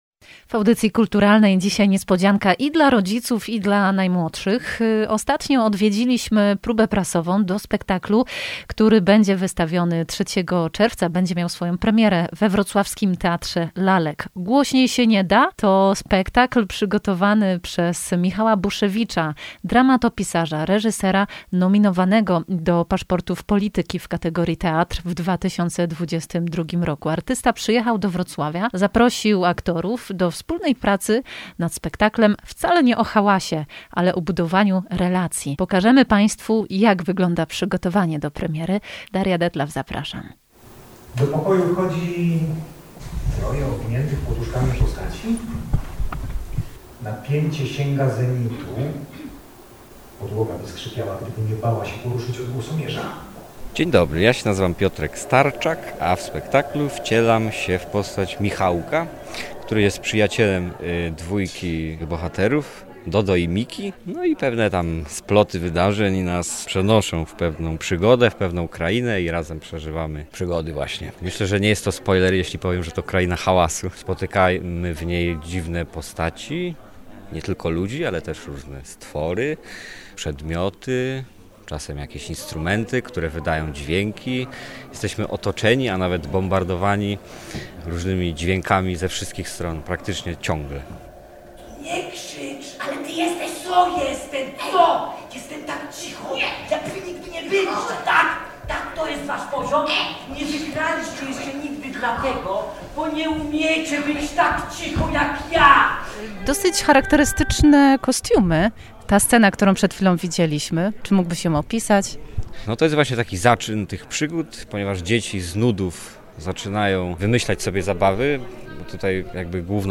Relacja z próby prasowej do „Głośniej się nie da?”.